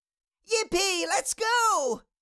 Cartoon Little Child, Voice, Yippee Lets Go Sound Effect Download | Gfx Sounds
Cartoon-little-child-voice-yippee-lets-go.mp3